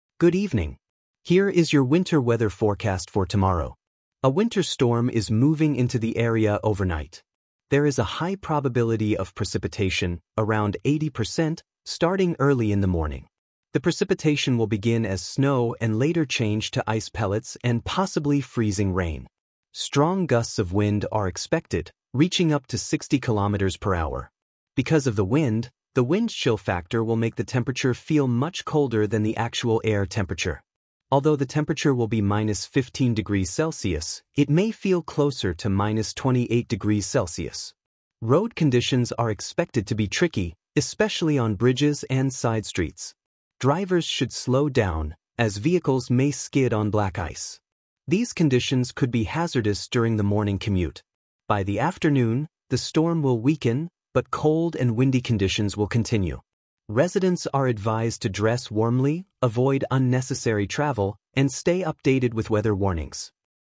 The Weather Forecast